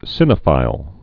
(sĭnə-fīl)